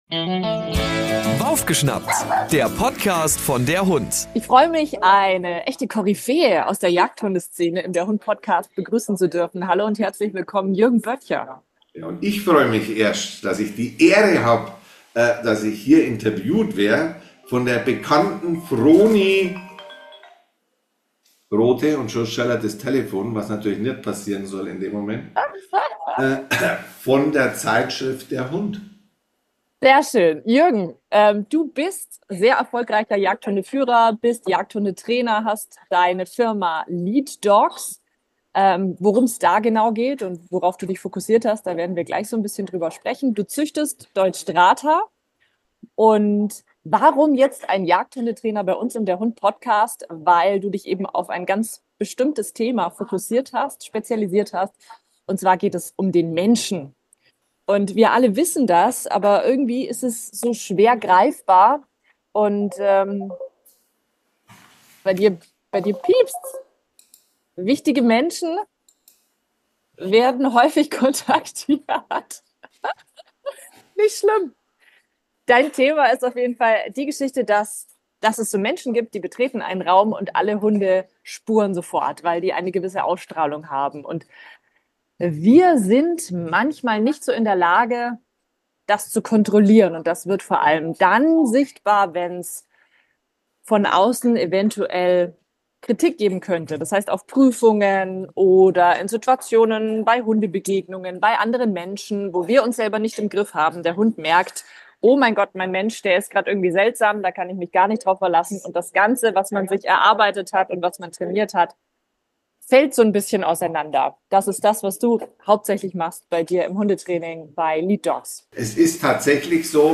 Waufgeschnappt ist DER Podcast von DER HUND. Die Redaktion des Magazins präsentiert euch alle zwei Wochen spannende Gespräche mit den bekanntesten Hundetrainern, Verhaltensberatern und Tierärzten und stellt eure Fragen, die ihr (vielleicht) immer schon einmal stellen wolltet!